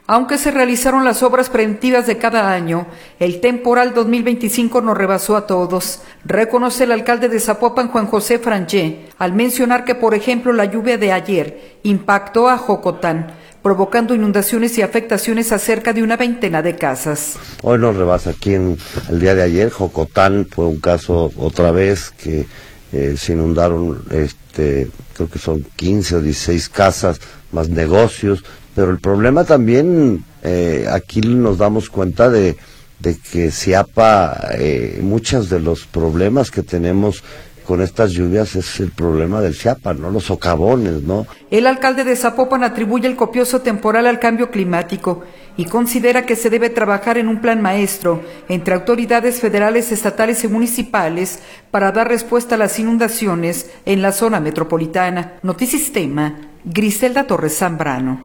audio Aunque se realizaron las obras preventivas de cada año el temporal 2025 nos rebasó a todos, reconoce el alcalde de Zapopan, Juan José Frangie al mencionar que por ejemplo la lluvia de ayer impactó a Jocotán, provocando inundaciones y afectaciones a cerca de una veintena de casas.